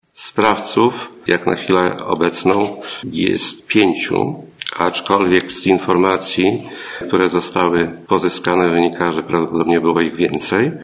– wyjaśnia Jan Mikucki, Prokurator Rejonowy w Ełku.